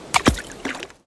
splash_low.wav